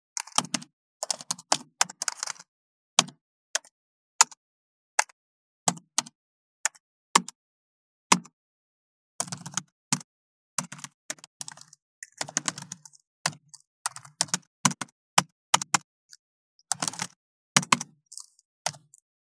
34.タイピング【無料効果音】
ASMRタイピング効果音
ASMR